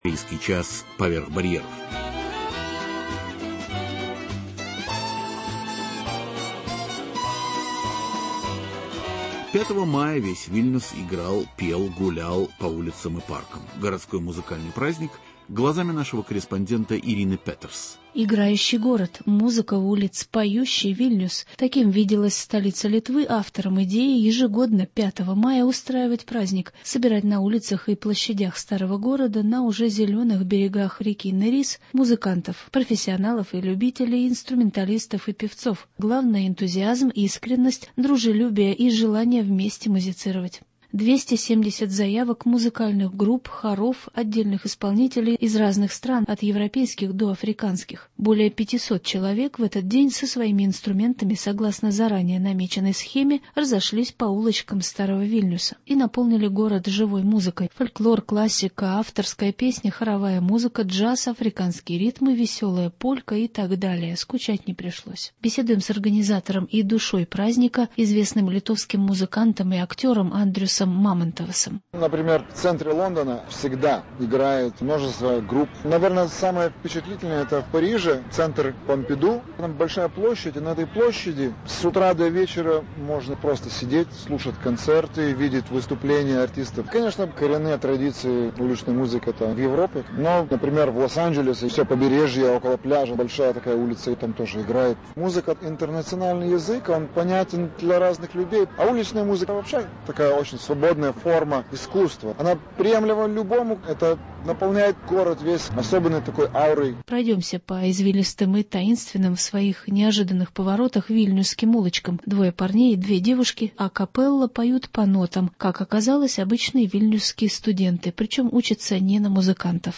Играющий и поющий Вильнюс – репортаж с городского праздника.